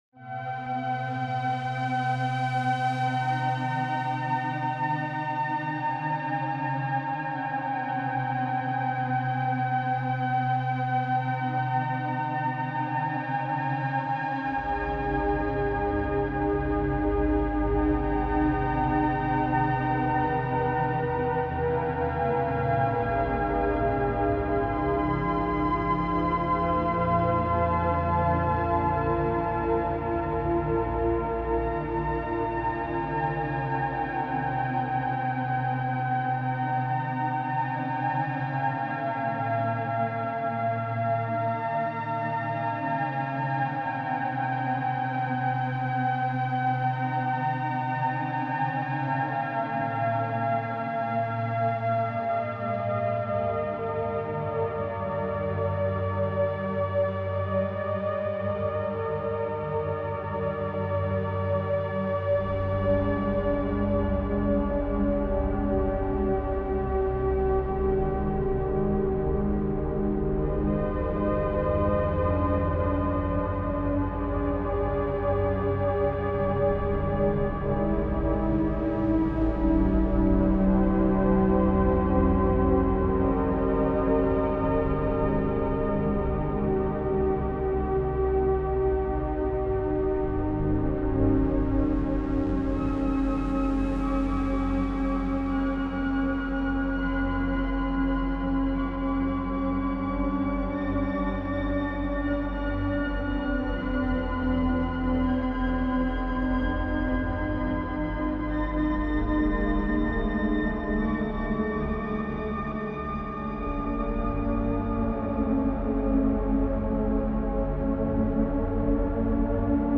DARK AMBIENT SPACE